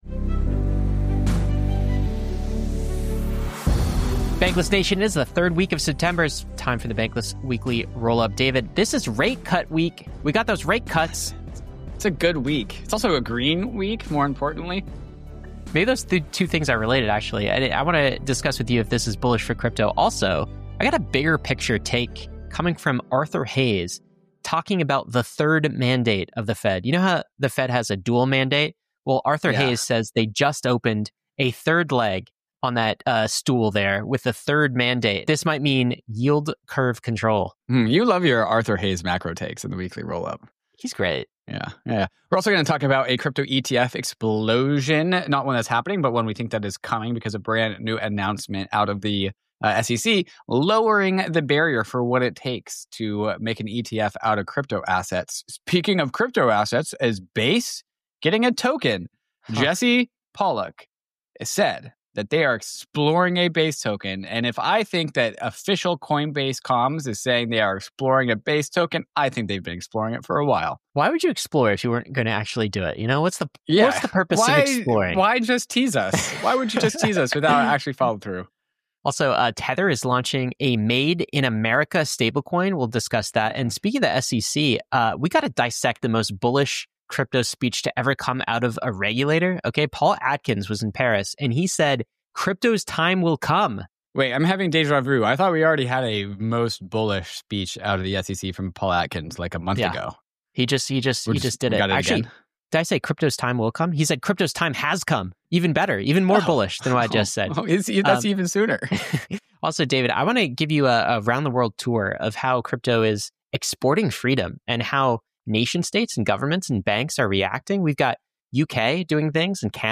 This conversation is packed with training insights and safety takeaways.